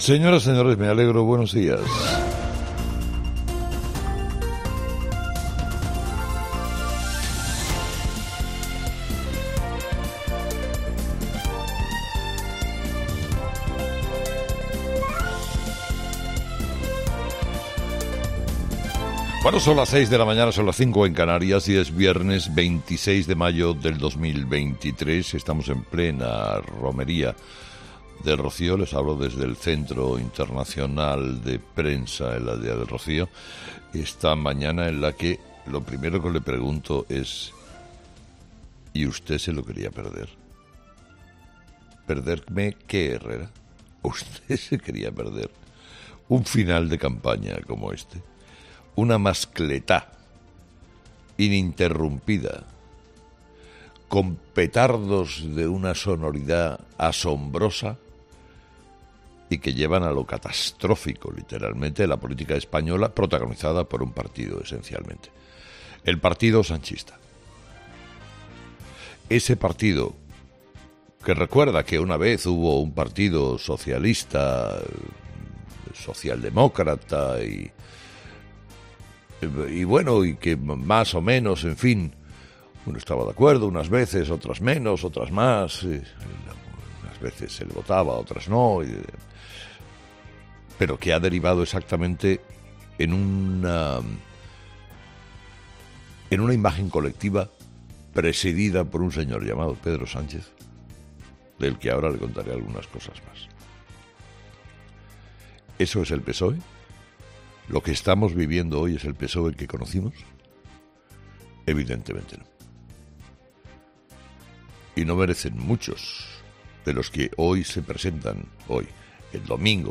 AUDIO: Carlos Herrera repasa los principales titulares que marcarán la actualidad de este viernes 26 de mayo en nuestro país